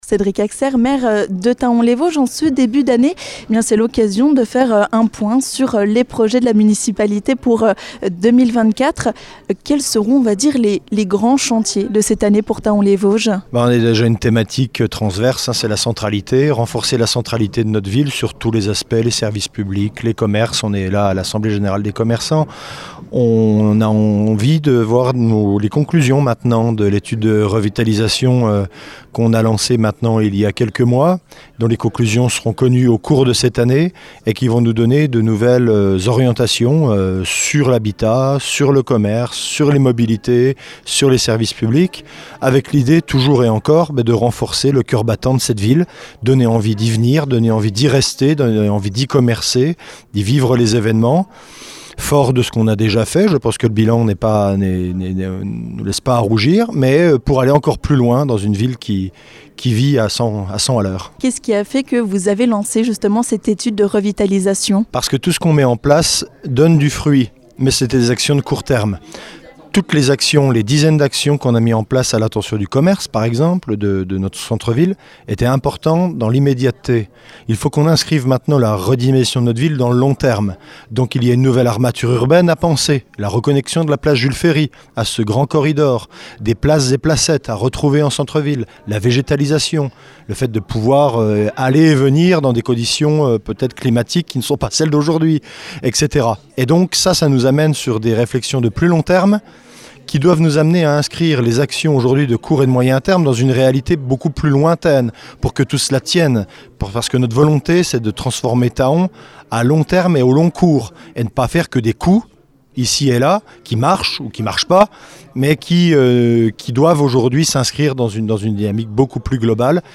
Lors de l'assemblée générale de l'Association des Commerçants et des Artisans de Thaon et Alentours, nous avons tendu notre micro au maire de la commune, Cédric Haxaire. L'occasion de lui demander quels sont les grands projets de la Ville de Thaon-les-Vosges.